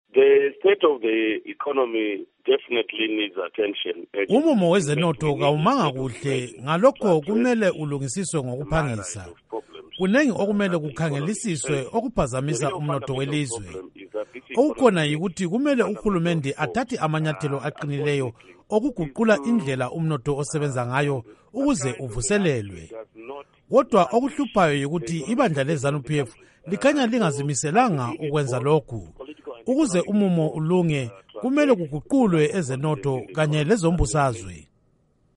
Ingxoxo loMnu Morgan Tsvangirai